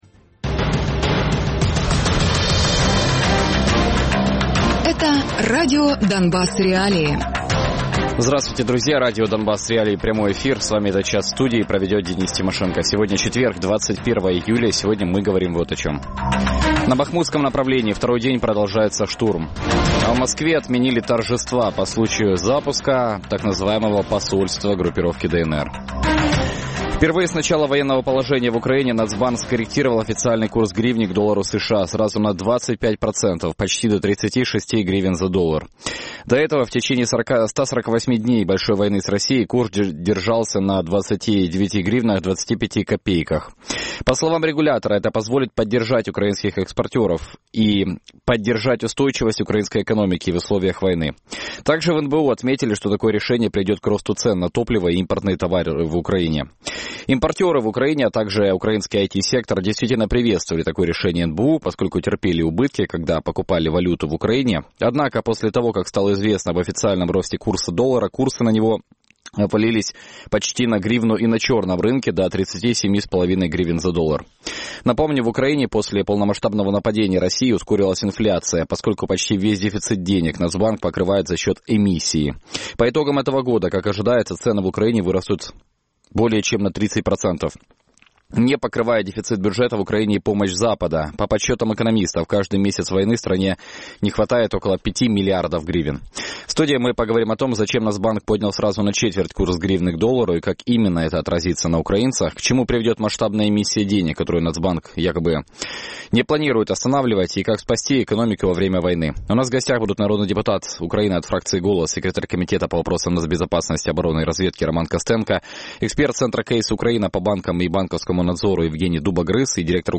Гості: Роман Костенко - народний депутат від фракції «Голос», секретар Комітету з питань національної безпеки, оборони і розвідки